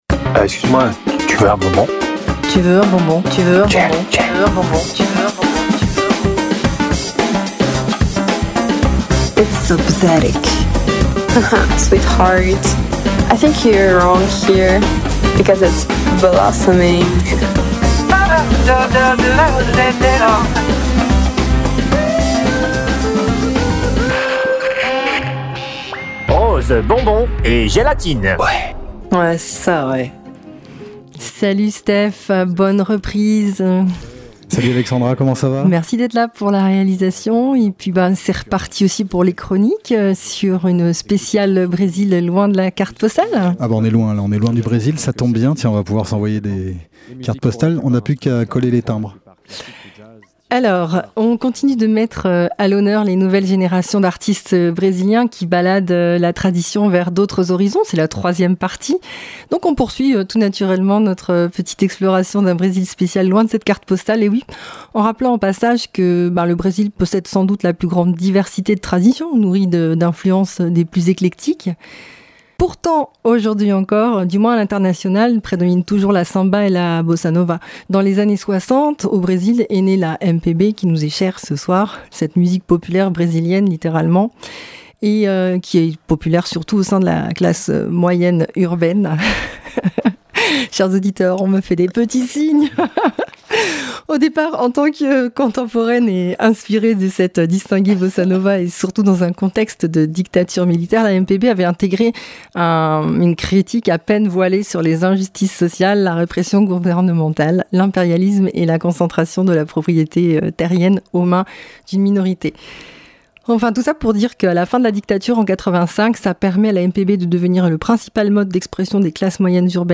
Troisième partie pour ce thème spécial Brésil "Loin de la Carte Postale" Bossa Samba!
On re-pioche allègrement dans l’immense proposition de la MPB musique populaire brésilienne ! La MPB a pour vocation de revivifier tous les styles brésiliens traditionnels en les revisitant inexorablement.